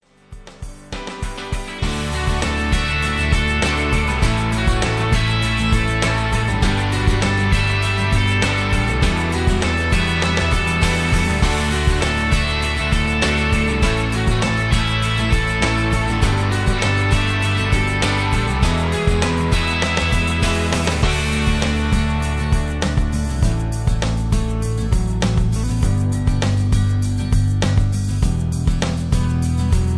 rock and roll